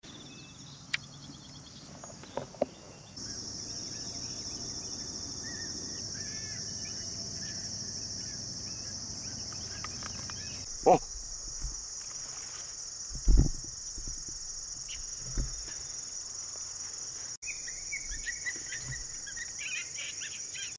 Coyote Social Sounds And Pup Sound Effects Free Download